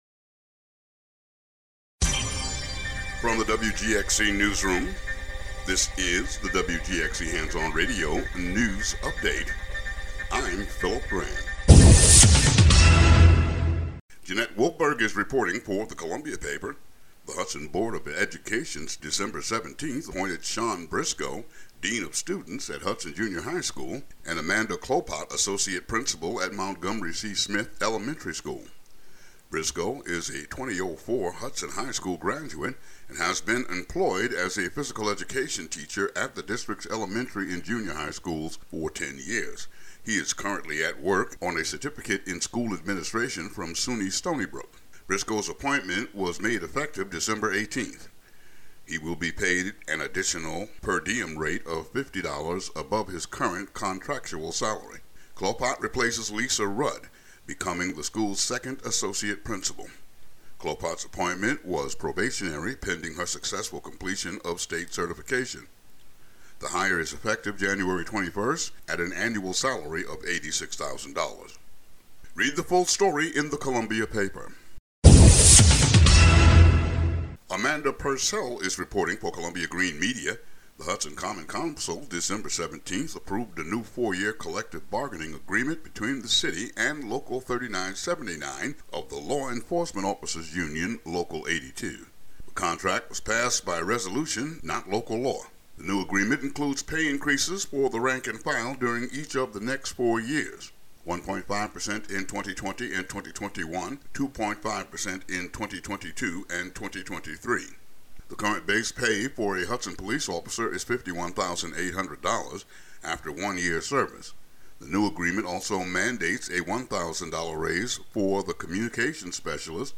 The audio version of the local news for Thu., Dec. 26.